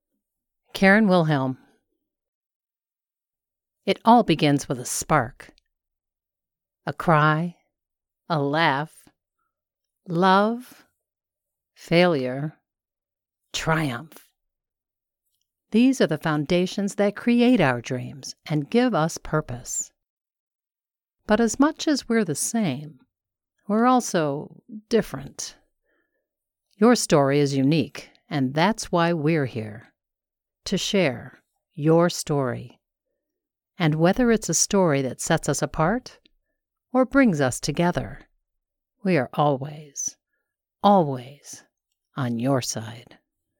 Engaging, Witty & Wise... Voice Over Talent for all your needs!
Singing Style Samples